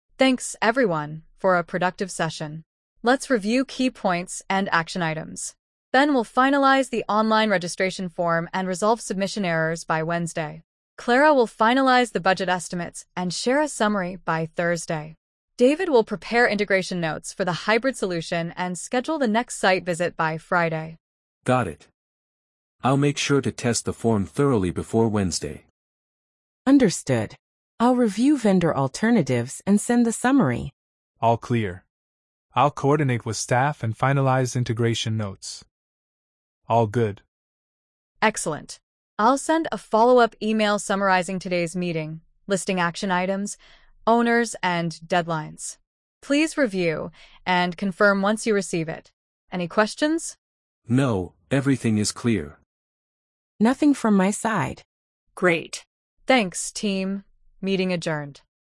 🤝 The team reviews action items and confirms responsibilities.